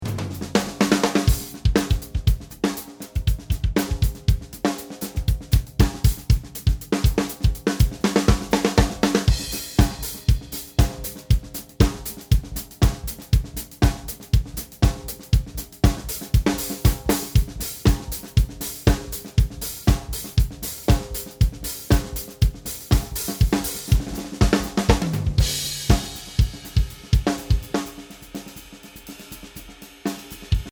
Here you have a few audios with non-processed drums, to listen the 3 different options you can choose.
We recorded these tracks with different tunings on the snare, but with the same drum kit: a Yamaha Hybrid Maple, sizes 14×6, 10, 12, 14, 20 pretty low tension on toms and floor tom, and softly muffled bass drum.